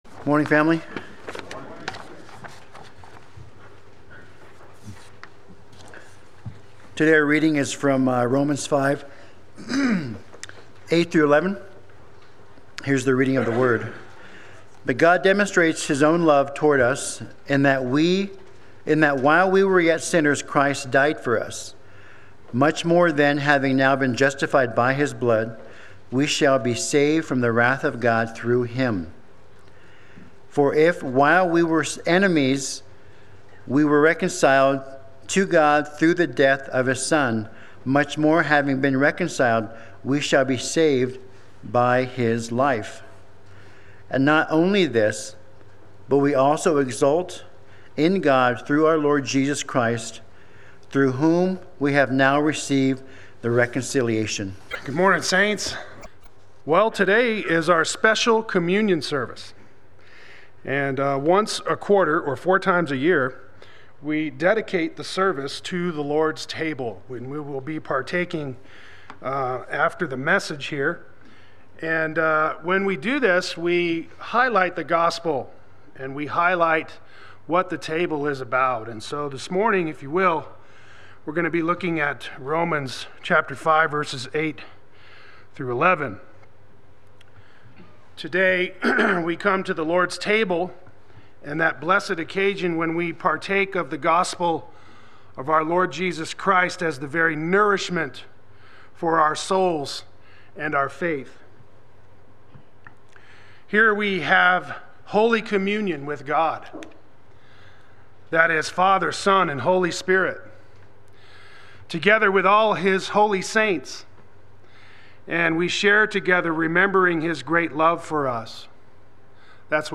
Play Sermon Get HCF Teaching Automatically.
Saved Sunday Worship